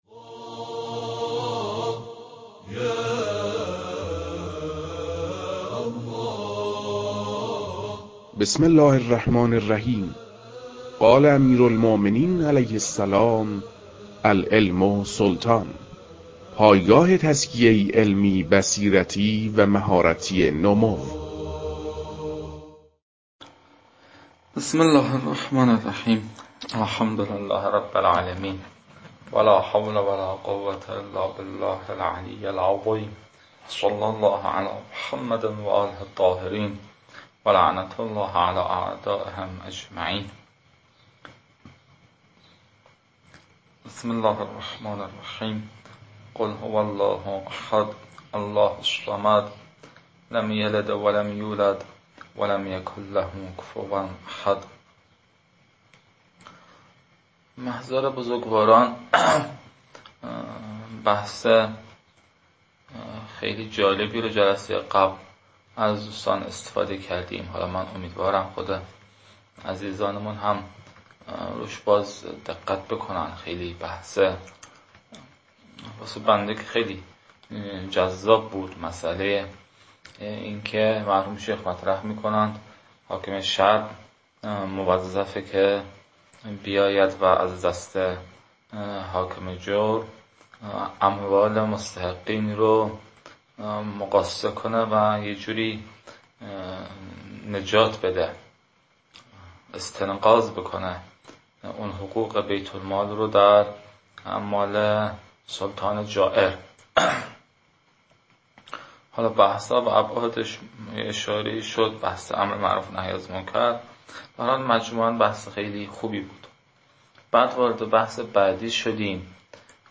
در این بخش، فایل های مربوط به تدریس مبحث المسألة الثانية جوائز السلطان و عمّاله از خاتمه كتاب المكاسب متعلق به شیخ اعظم انصاری رحمه الله